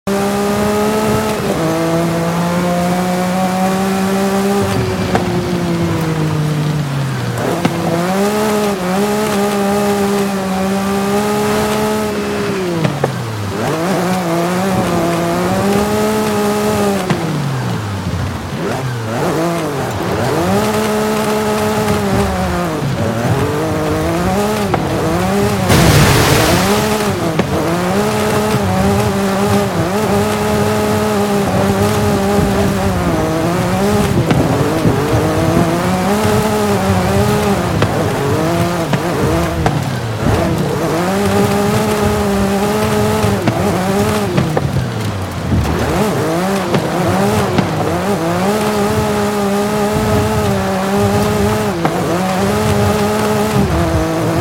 Maximum Attack Subaru 22B Sti sound effects free download
Maximum Attack Subaru 22B Sti Pure Sound